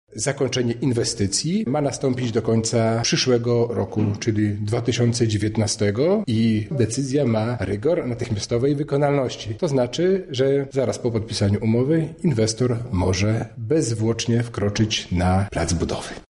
O tym, jak droga wyglądać będzie po remoncie, mówi wicewojewoda lubelski Robert Gmitruczuk: